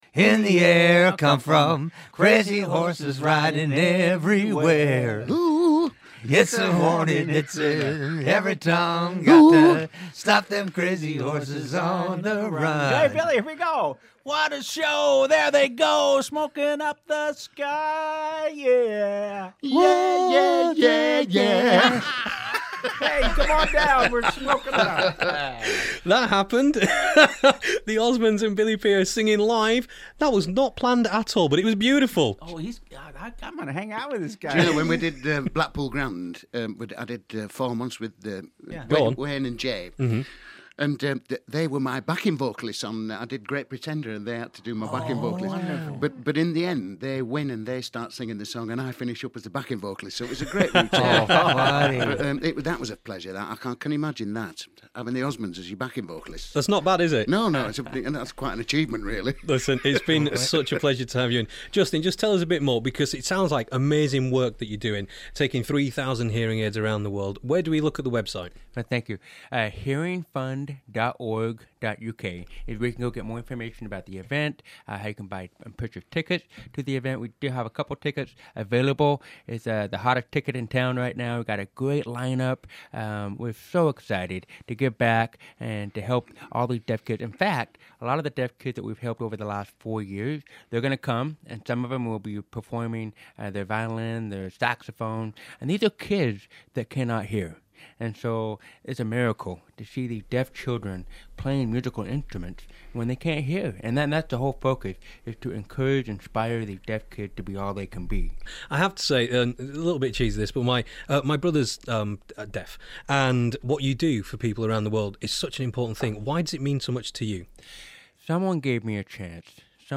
The Osmonds visited us in the studio ahead of their charity gala at Elland Road on Saturday 8th August. They're raising money for their charity, Hearing Fund UK.